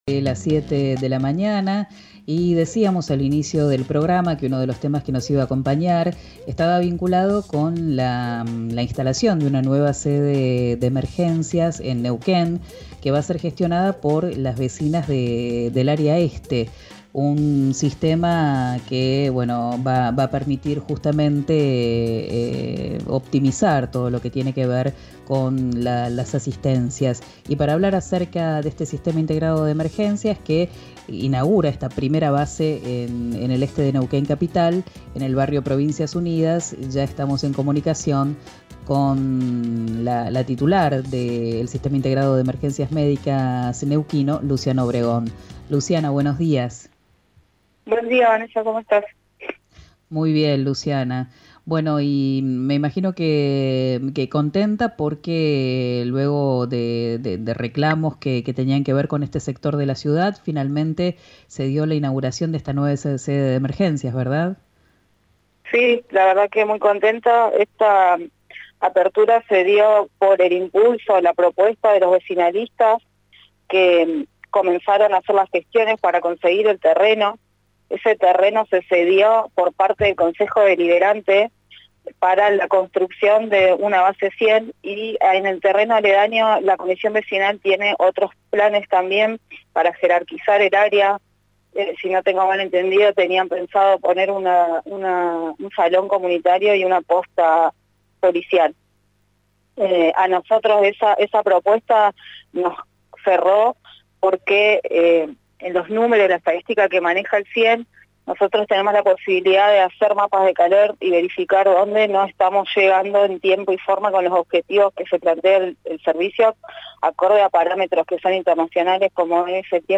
Escuchá a la titular del SIEN, Luciana Obregón, en RÍO NEGRO RADIO: